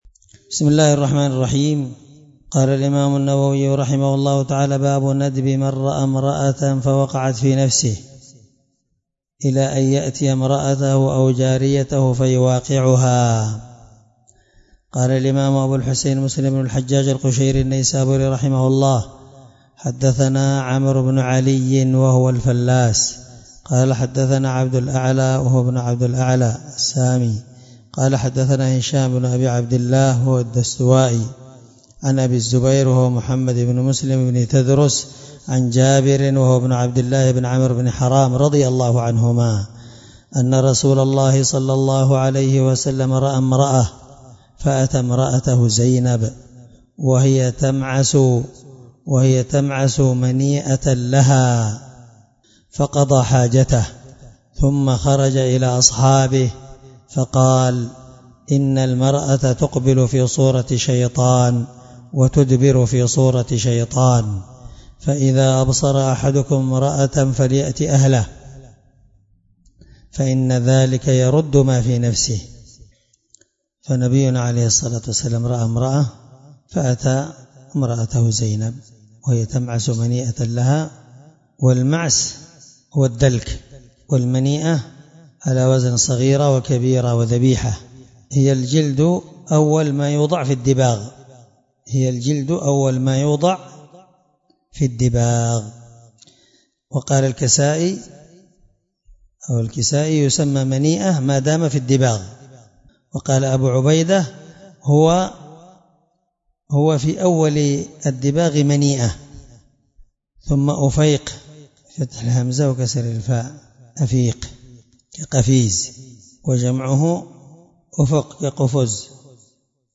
الدرس4من شرح كتاب النكاح حديث رقم(1403) من صحيح مسلم